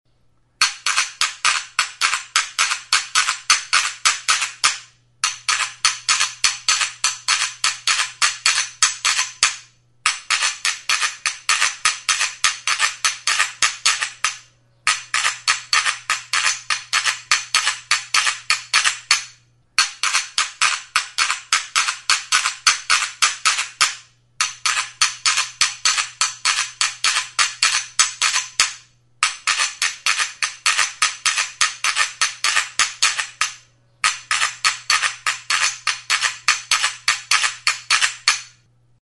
Instruments de musiqueCUNCHAS
Idiophones -> Frottés
Enregistré avec cet instrument de musique.
Vieiraren 2 kontxa zimurtsu dira.